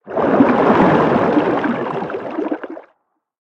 Sfx_creature_jellyfish_swim_os_04.ogg